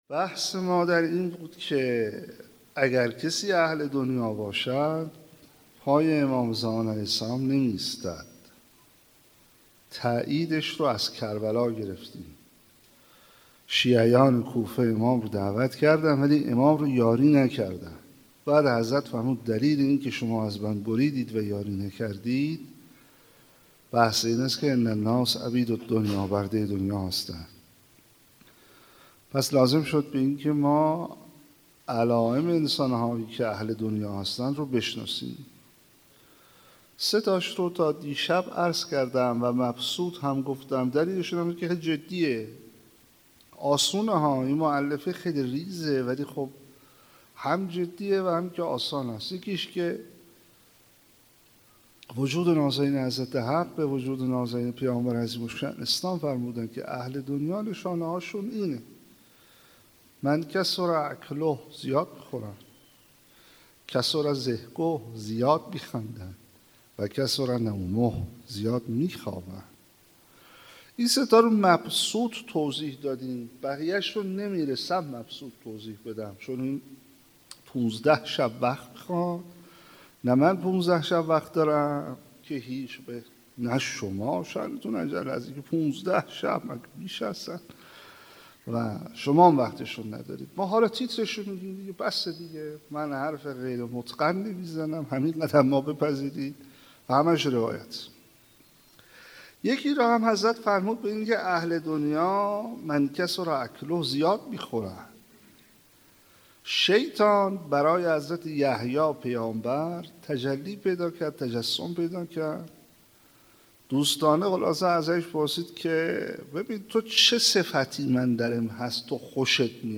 25 بهمن 96 - هیئت ثارالله - سخنرانی